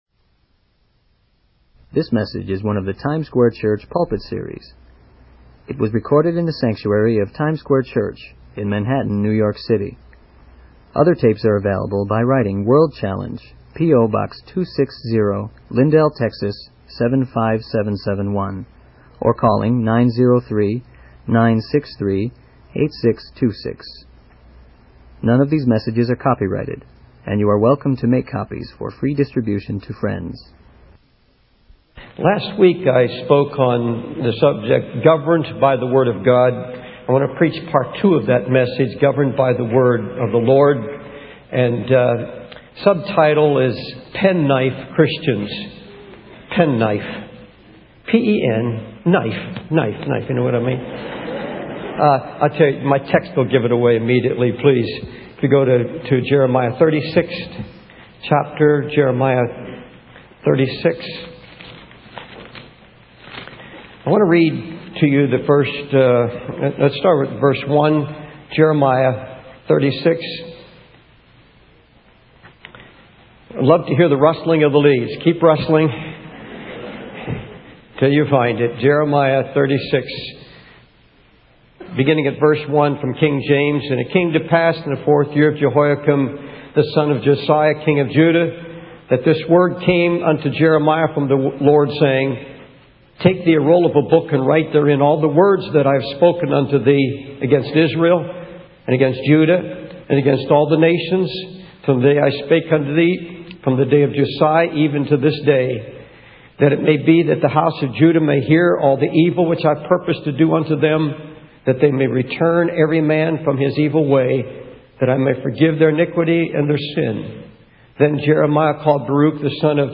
In this sermon, the preacher expresses his concern about young ministers and people who preach without fully relying on the Word of God. He emphasizes the importance of preaching the whole counsel of God and not picking and choosing what to preach based on personal preferences.